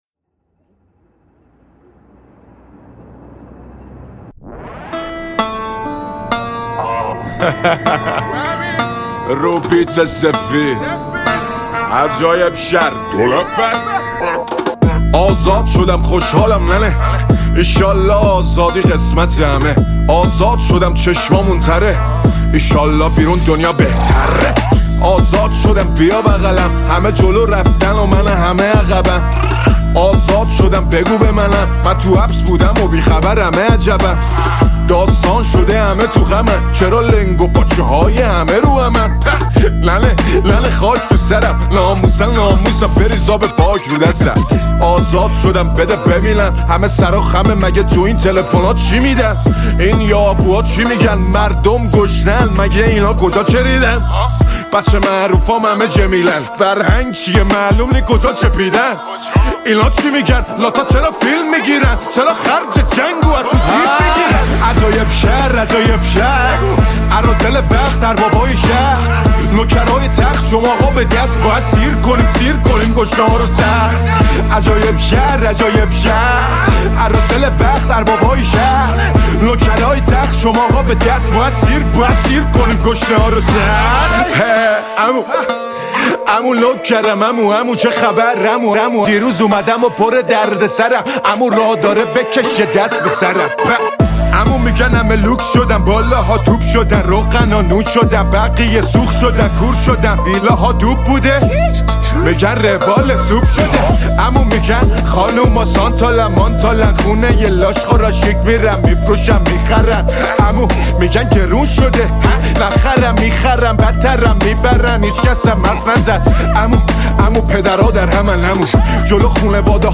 متن آهنگ رپ